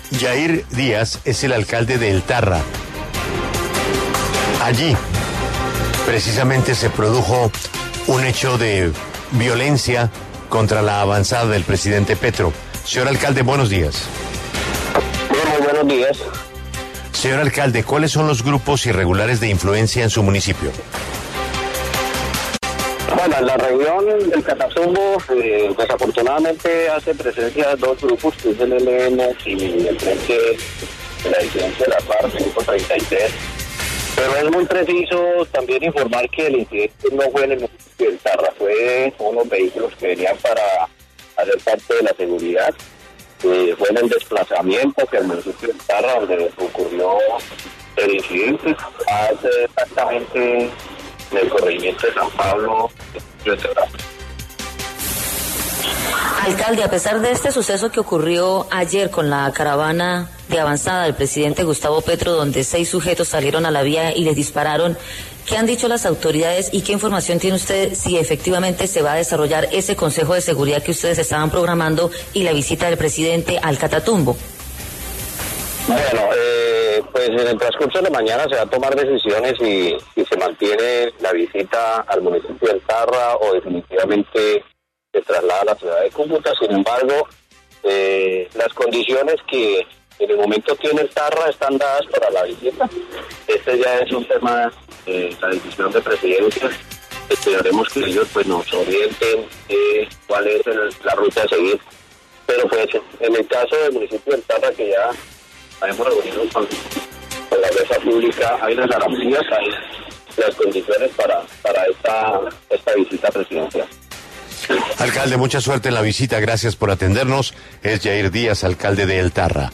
Jair Díaz Peñaranda, alcalde del municipio de El Tarra, conversó en La W sobre el reciente ataque a una caravana de avanzada de seguridad del presidente Gustavo Petro.